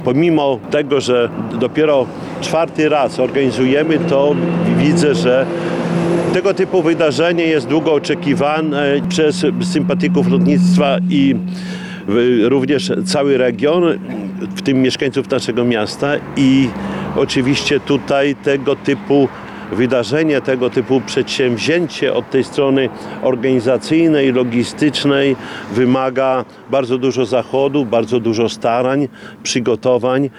Włodarz zapowiedział imprezę podczas konferencji prasowej zorganizowanej w poniedziałek (26.05) w pobliżu lotniska. Wydarzenie rozpocznie się 28 czerwca na lotnisku imienia Witolda Urbanowicza w Suwałkach. Jak zaznaczył prezydent, organizacja takiej imprezy zawsze wiąże się z dużym nakładem pracy.